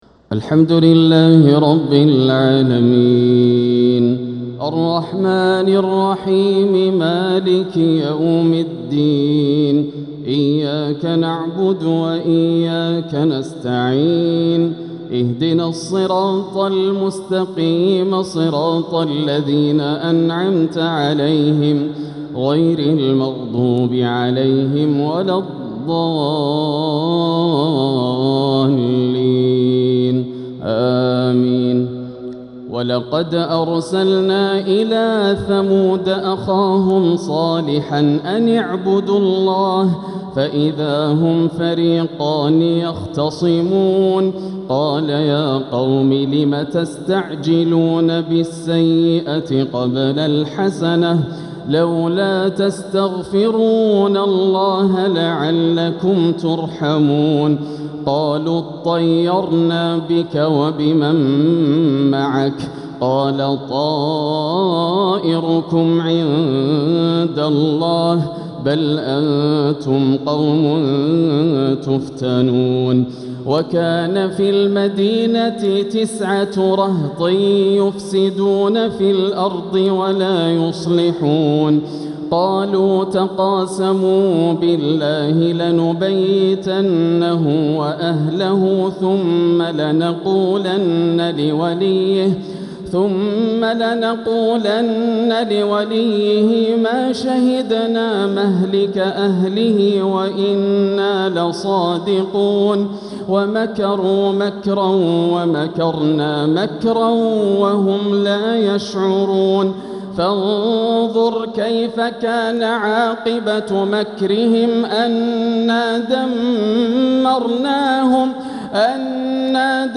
تلاوة من سورتي النمل (45-93) و القصص (1-13) | تراويح ليلة 23 رمضان 1446هـ > الليالي الكاملة > رمضان 1446 هـ > التراويح - تلاوات ياسر الدوسري